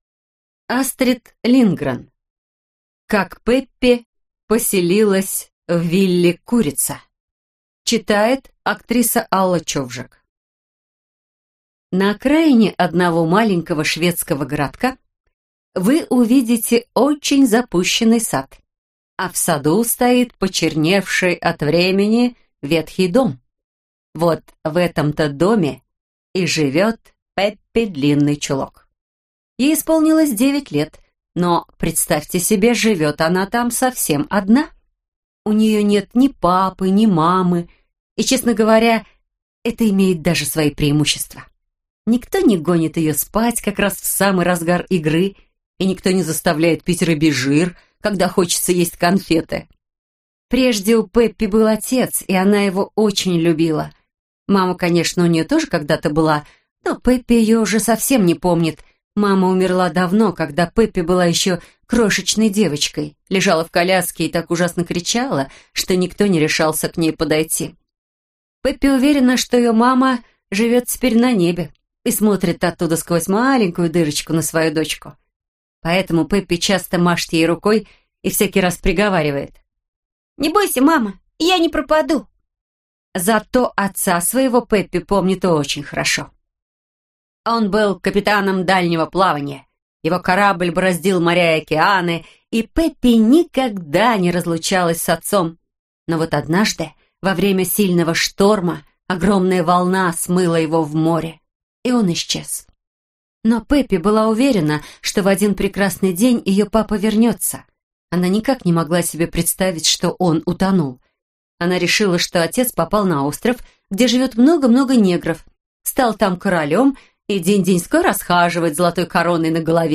Аудиокнига Пеппи Длинныйчулок поселяется на вилле «Курица» - купить, скачать и слушать онлайн | КнигоПоиск